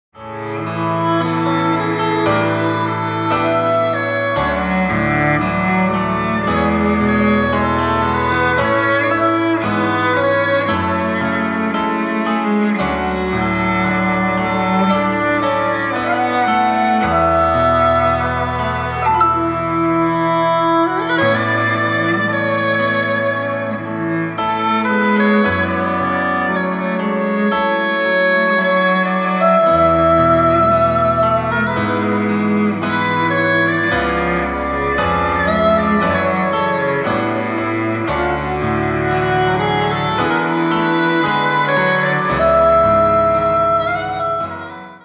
幾年月を重ね、成功の極みへ到達するサックスの残響、そして郷愁を超えて…。
サクソフォン｜ギター｜ヴォーカル｜作詞・作曲・編曲｜プロデュース